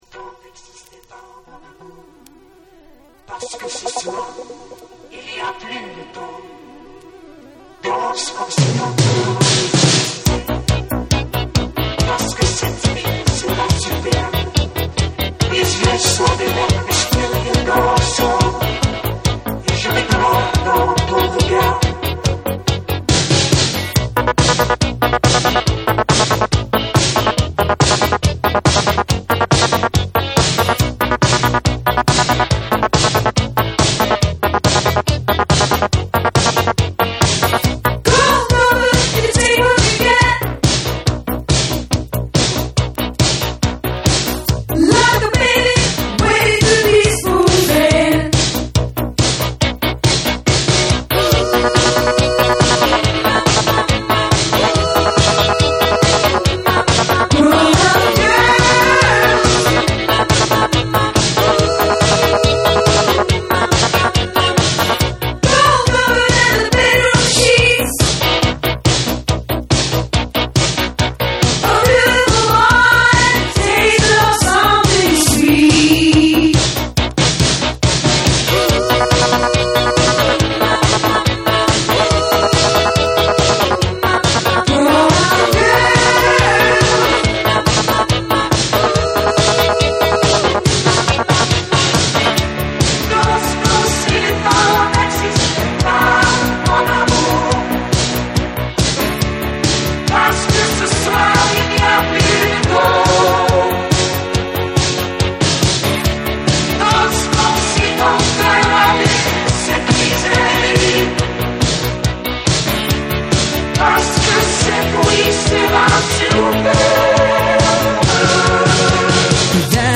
誰もが耳にしたことのある美しいコーラスでお馴染みの未だ色褪せない名曲
NEW WAVE & ROCK